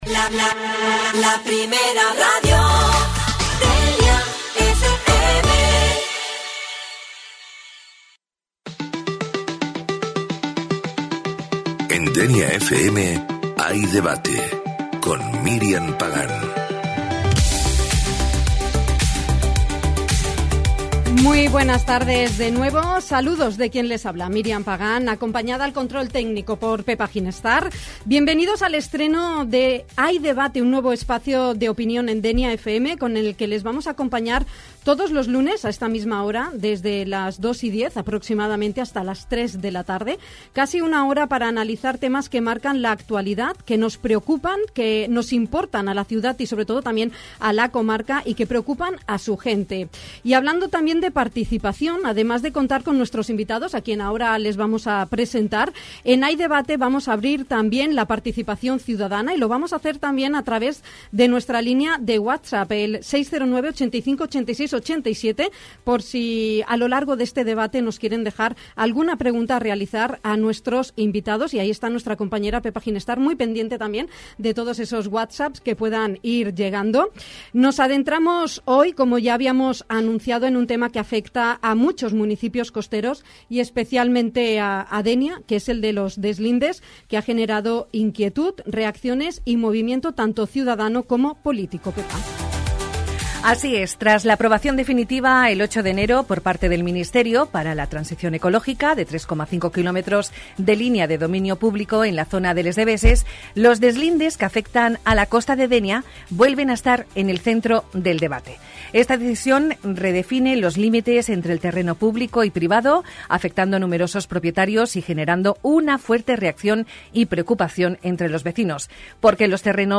RADIO DENIA FM: Debate sobre el deslinde en la playas de Dénia